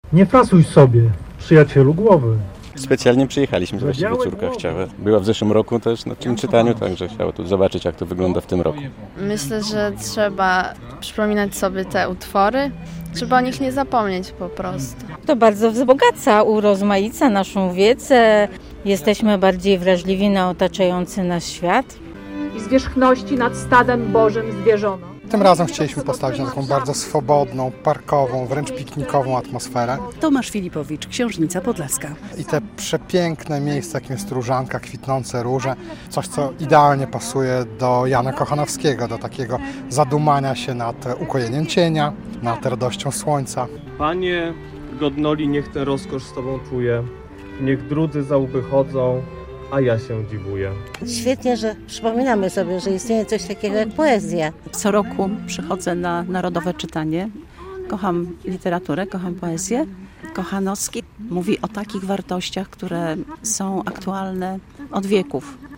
Narodowe Czytanie, Kochanowski w Różance - relacja